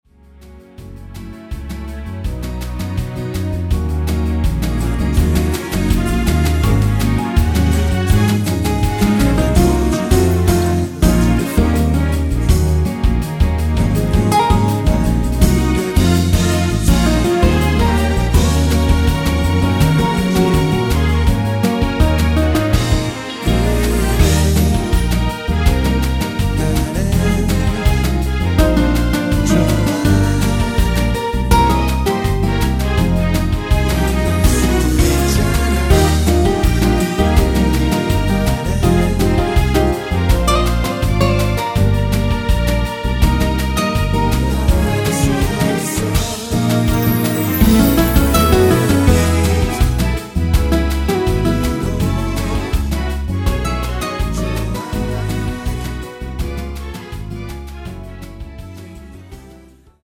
원키 코러스 포함된 MR 입니다.(미리듣기 참조)
Db
앞부분30초, 뒷부분30초씩 편집해서 올려 드리고 있습니다.
중간에 음이 끈어지고 다시 나오는 이유는